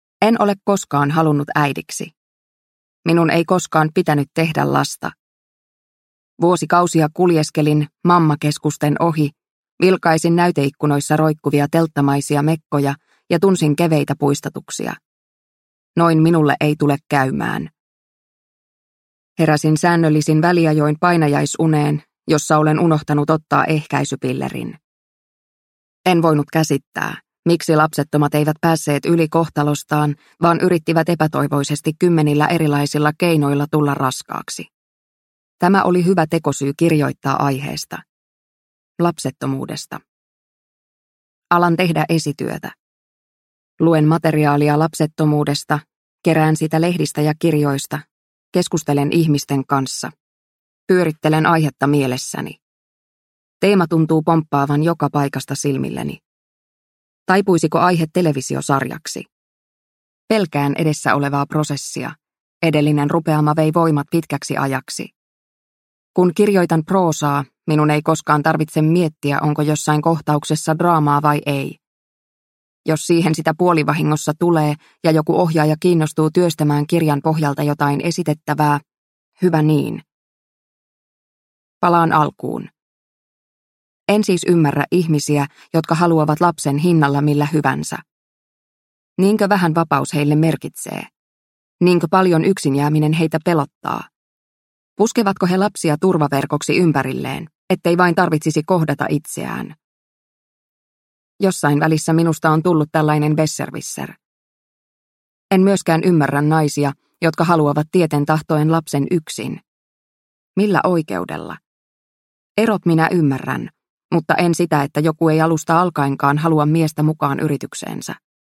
Heikosti positiivinen – Ljudbok – Laddas ner